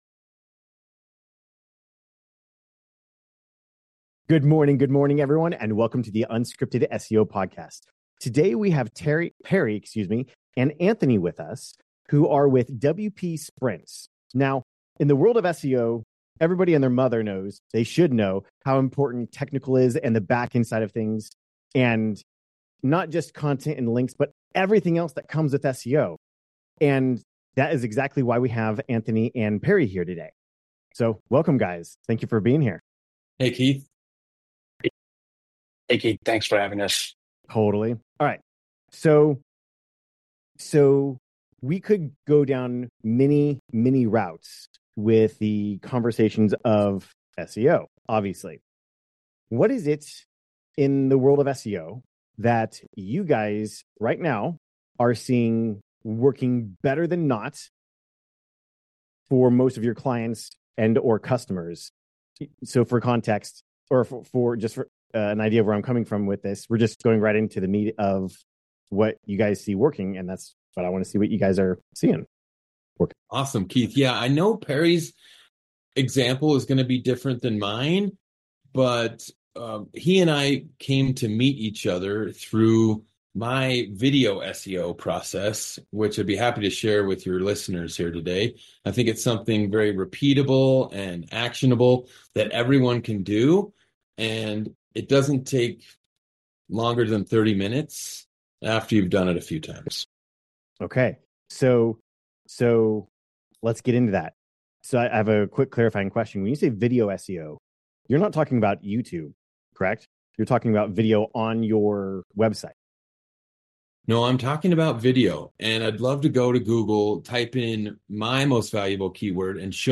100% unscripted, 100% unrehearsed, 100% unedited, and 100% real.